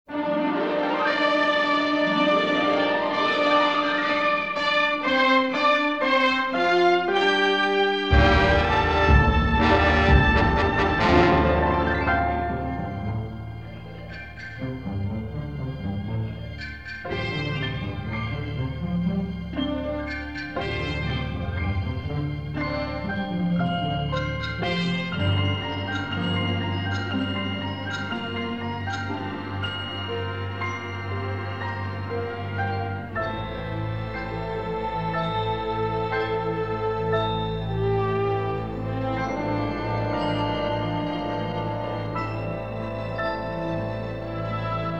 haunting Asian textures and harmonies
The entire CD is in stereo